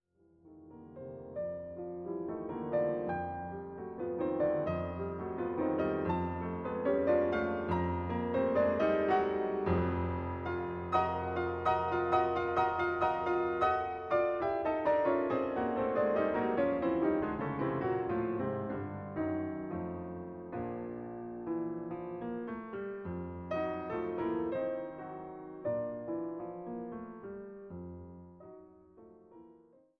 Nr. 20 in c-Moll Largo